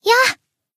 BA_V_Tsubaki_Battle_Shout_1.ogg